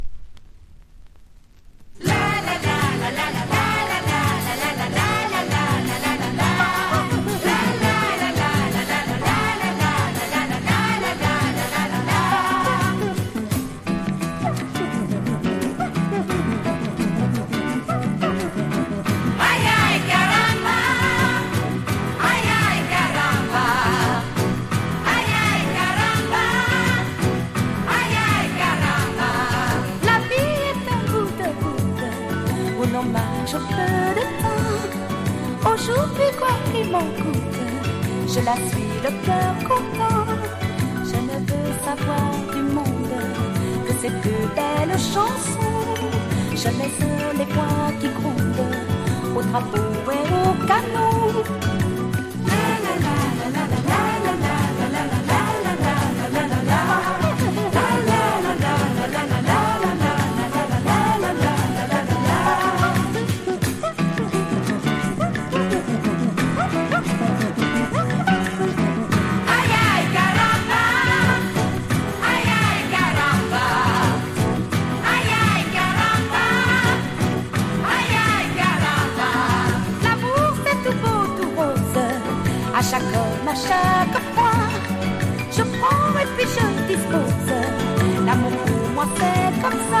ナイスなフレンチ・ブラジリアン。